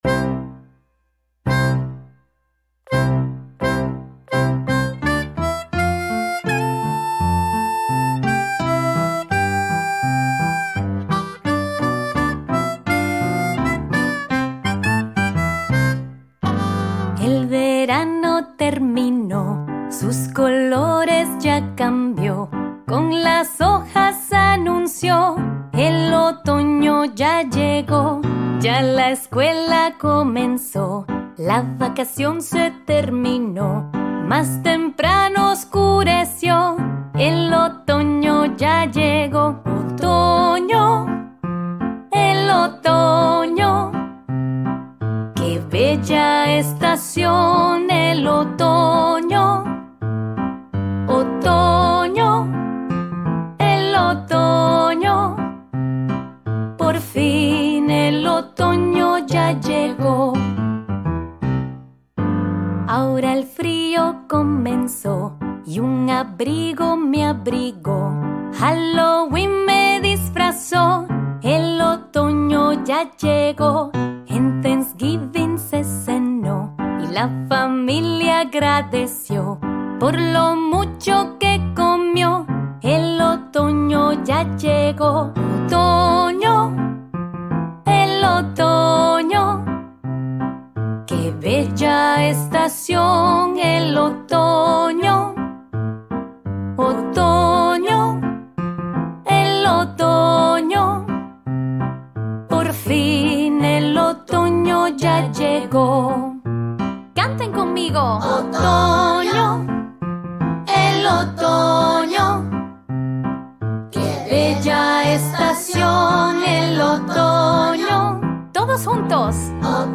Esta pegadiza canción infantil
Una estupenda canción a coro para todas las edades.